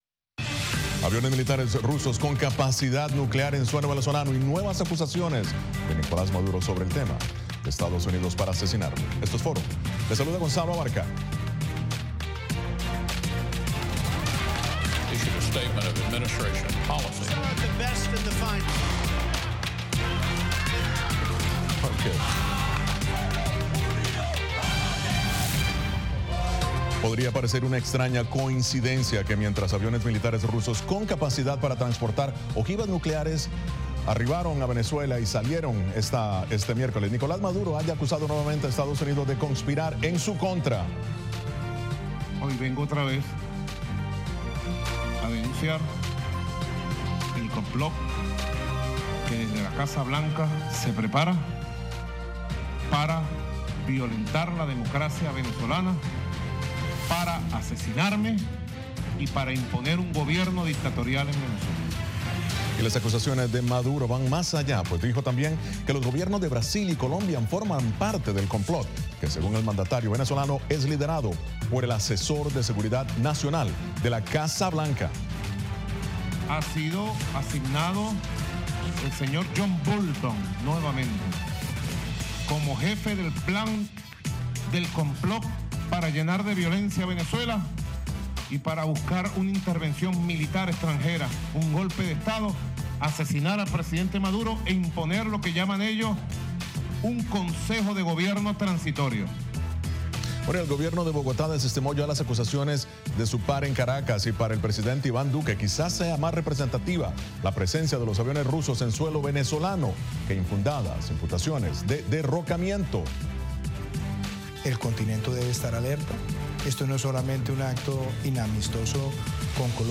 Programa de análisis de treinta minutos de duración con expertos en diversos temas.